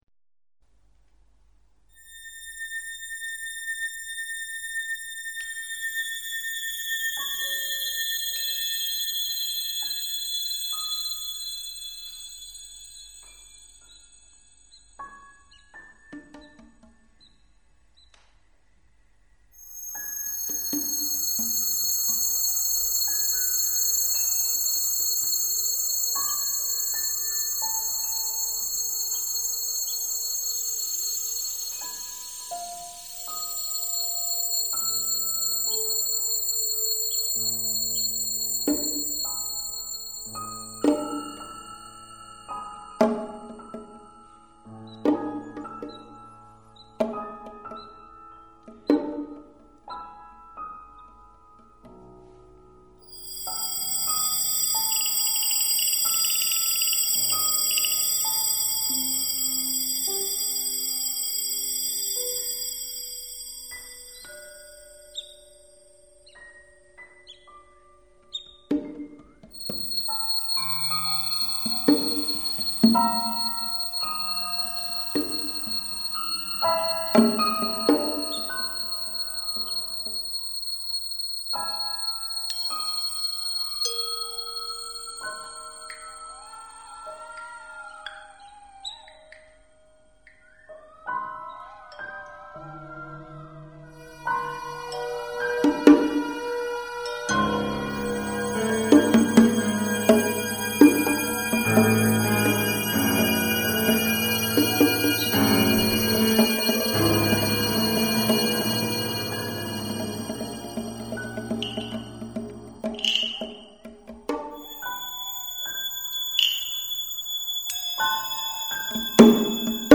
Per strumenti vari (acustici e elettronici), voce femminile
con Raddoppio per percussioni e pianofore
Registrazione DDD presso Centro Studi Assenza - Marzo 2001